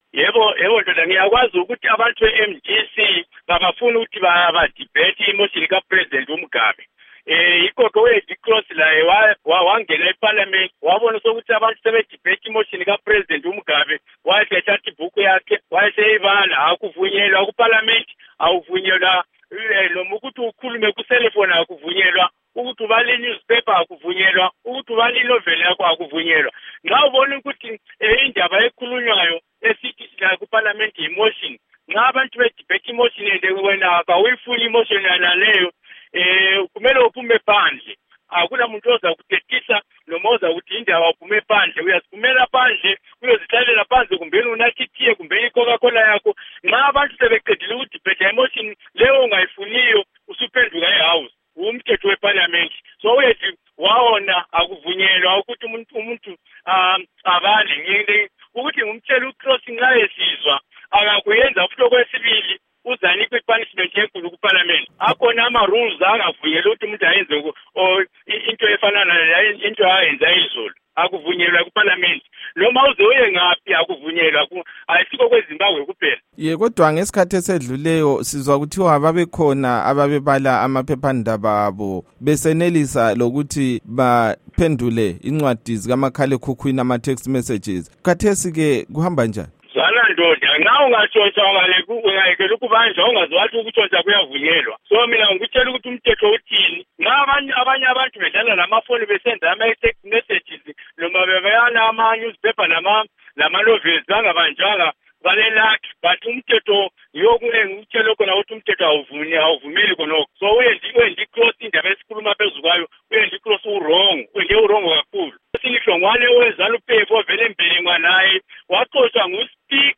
Ingxoxo loMnu. Joram Gumbo